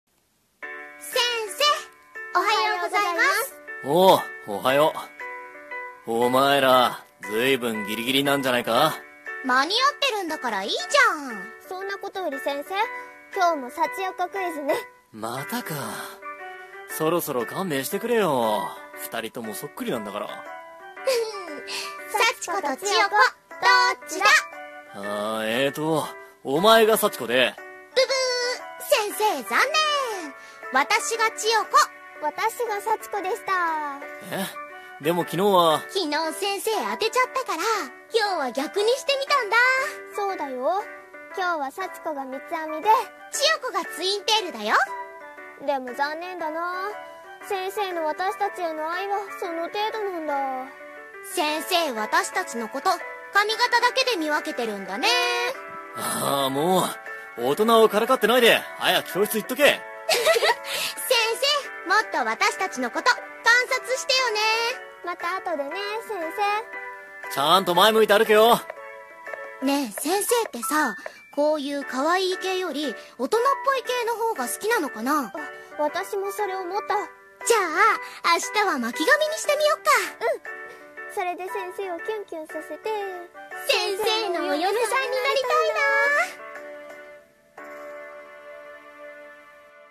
【コラボ声劇】恋するサチヨコクイズ